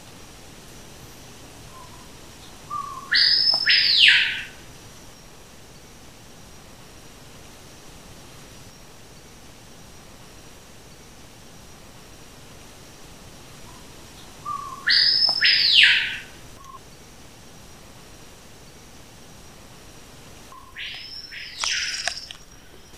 Cricrió (Lipaugus vociferans)
Nome em Inglês: Screaming Piha
Detalhada localização: Estação Ecológica de Murici
Condição: Selvagem
Certeza: Gravado Vocal